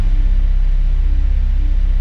DM PAD2-52.wav